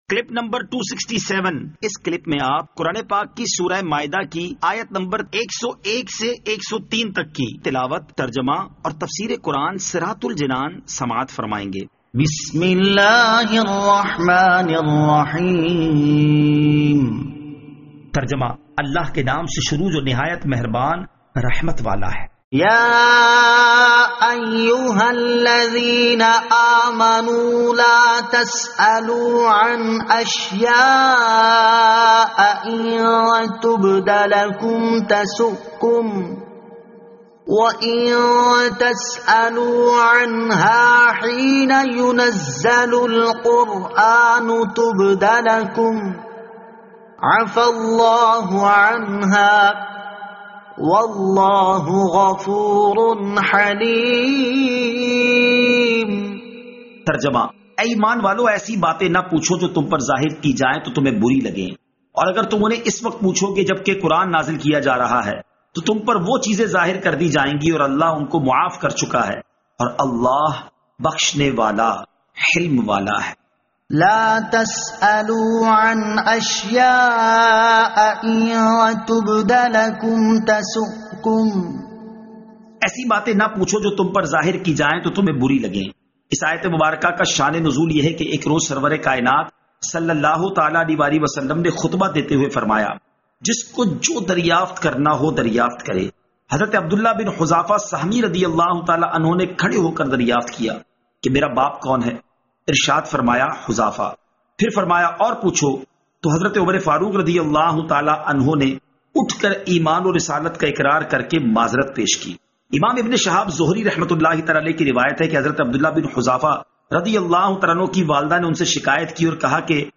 Surah Al-Maidah Ayat 101 To 103 Tilawat , Tarjama , Tafseer